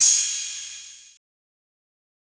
Crashes & Cymbals
Crash.wav